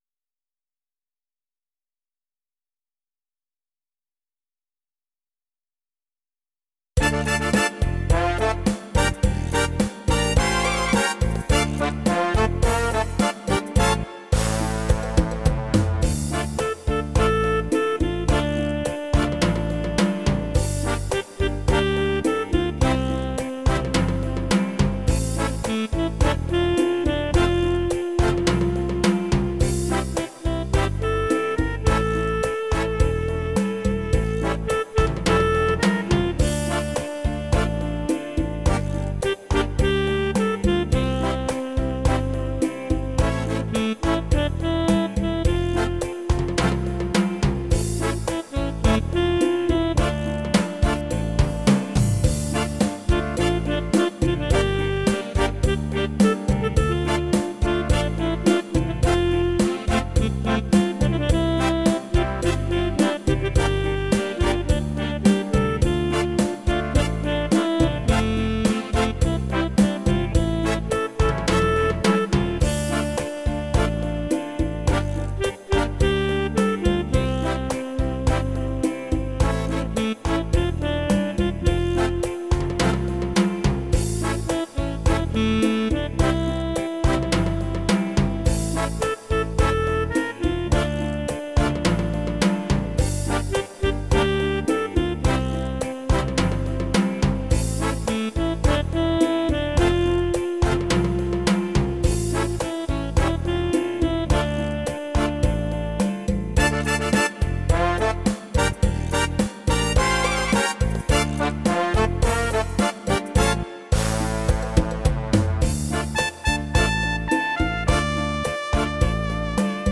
Arrangement & Keyboard
Cha Cha Cha